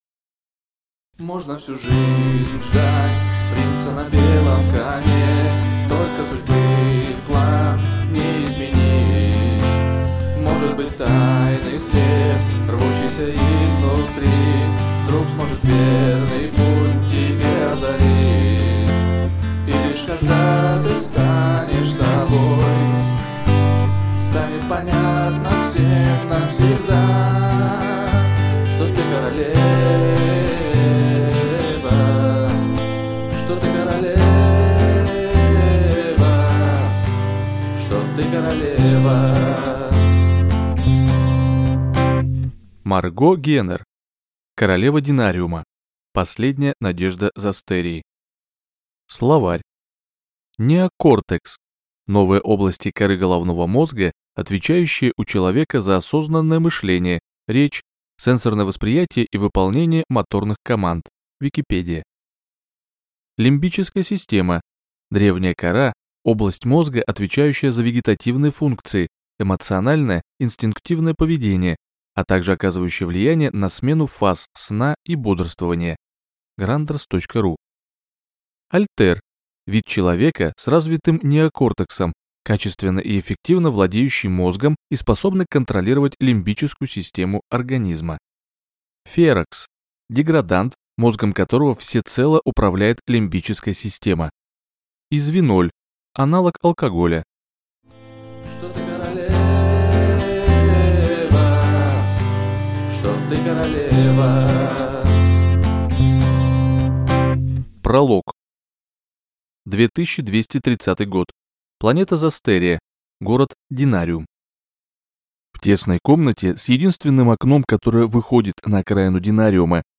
Аудиокнига Королева Динариума. Последняя надежда Застерии | Библиотека аудиокниг